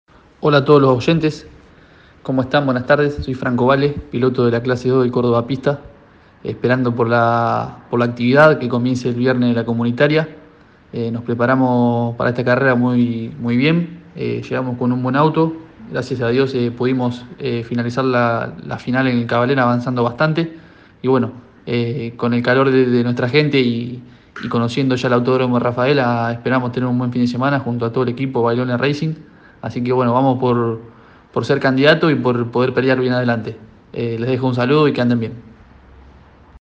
Los pilotos santafesinos realizaron declaraciones antes de la quinta fecha.